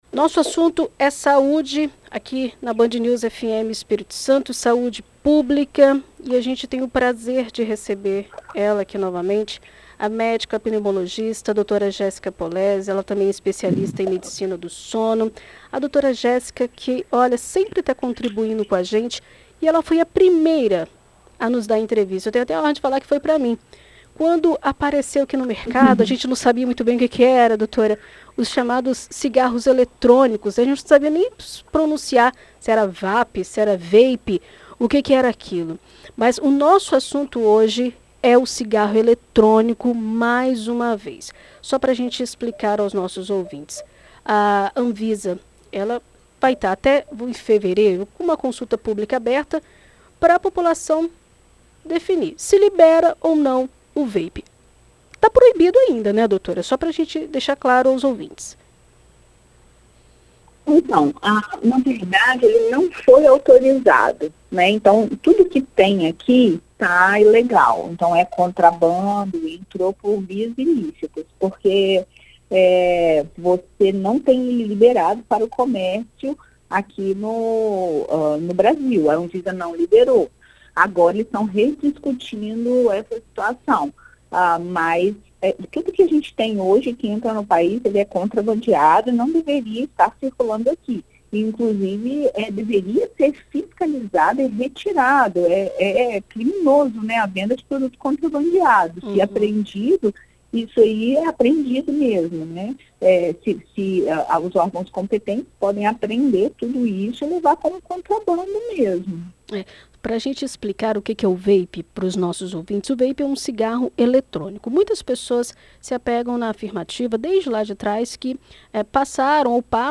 Em entrevista à BandNews FM ES